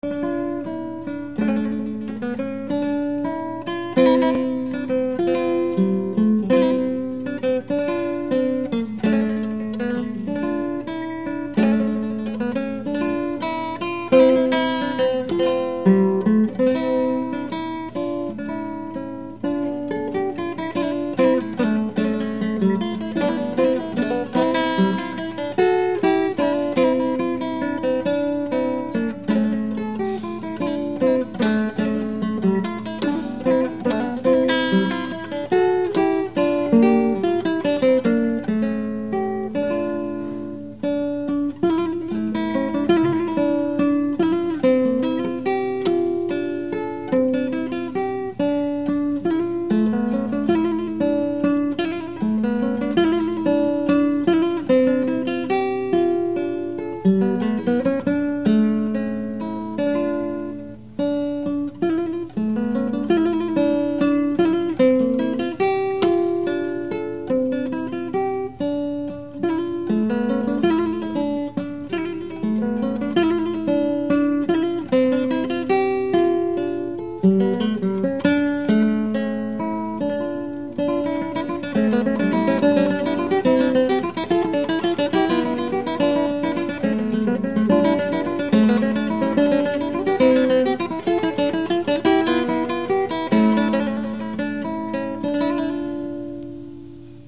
These were recorded live at various performances using whatever recording equipment I had access to at the time, and as a result, the quality is not the best.
Baroque Guitar (played on a modified classical guitar as described in my article, "Baroque Guitar for the Modern Performer")